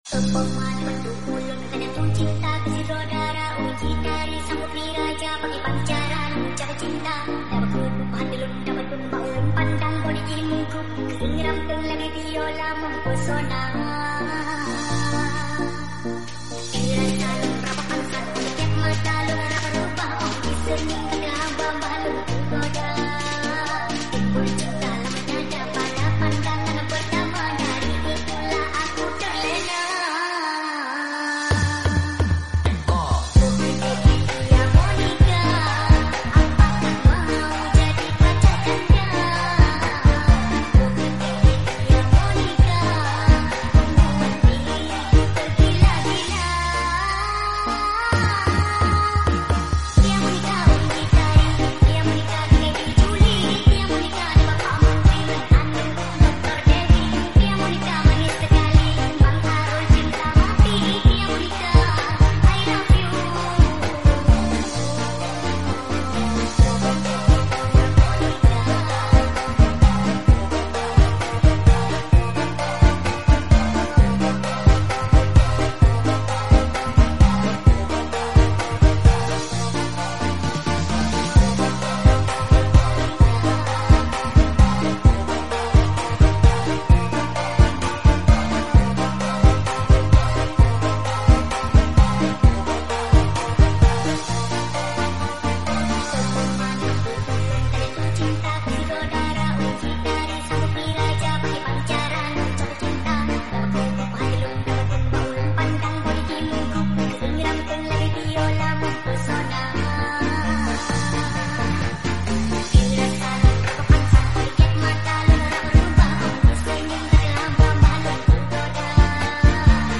versi jepang full bass full durasi